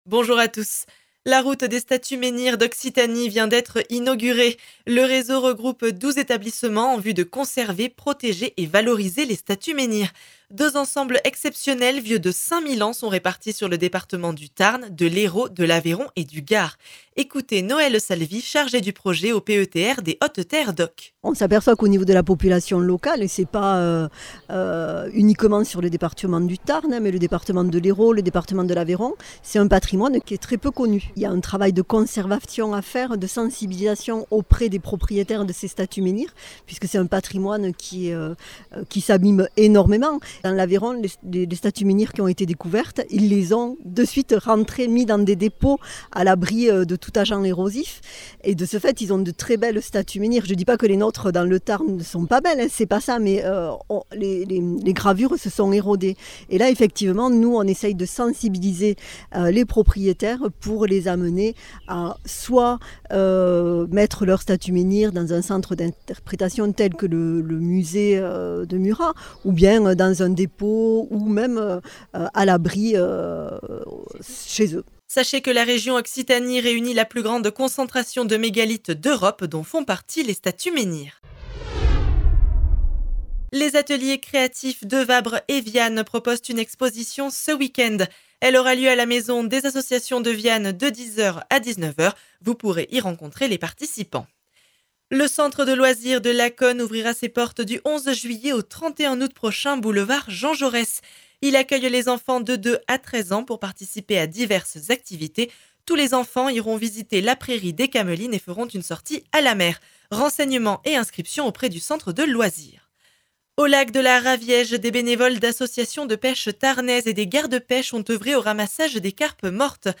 Flash Monts de Lacaune 24 juin 2022